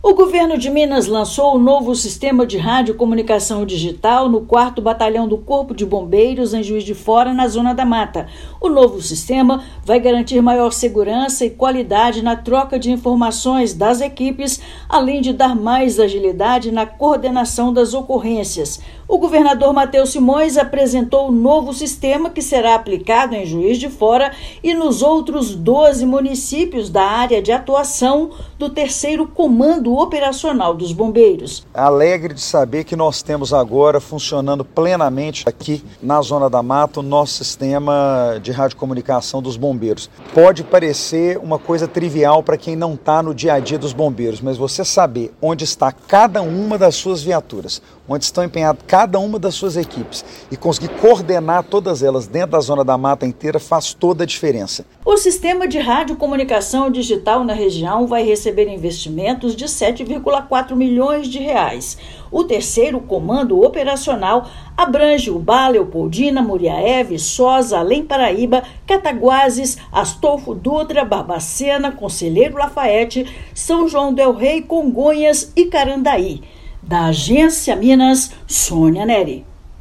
[RÁDIO] Governo de Minas lança novo sistema de radiocomunicação do Corpo de Bombeiros na Zona da Mata
Investimento de cerca de R$ 7,4 milhões irá garantir mais segurança para equipes e população da região em emergências. Ouça matéria de rádio.